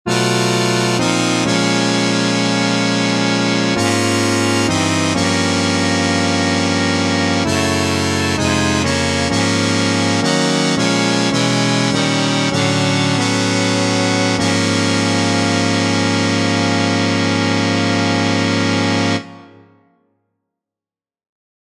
Key written in: D♭ Major
How many parts: 4
Type: Barbershop
All Parts mix: